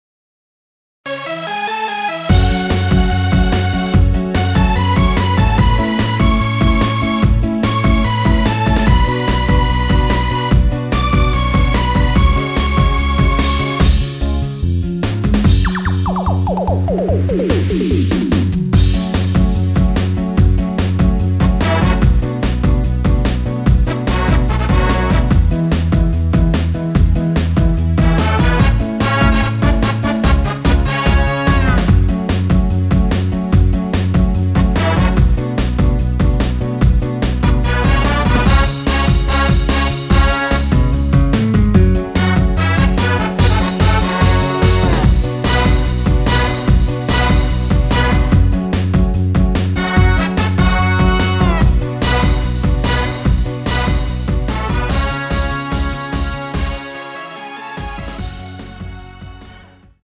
F#m
앞부분30초, 뒷부분30초씩 편집해서 올려 드리고 있습니다.
중간에 음이 끈어지고 다시 나오는 이유는